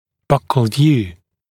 [ˈbʌkl vjuː][ˈбакл вйу:]вид в боковой проекции (со стороны боковых зубов)